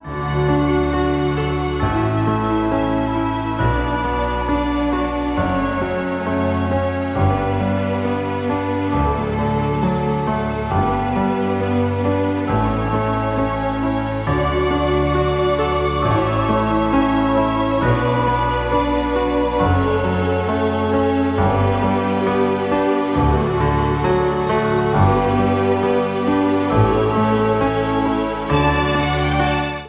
• Holiday